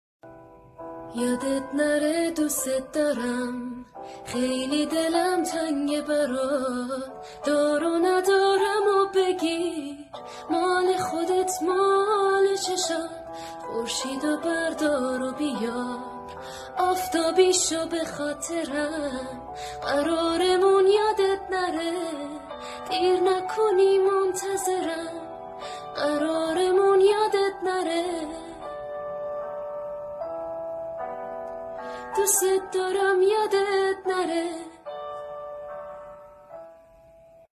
با صدای زن (تمام خوانندگان)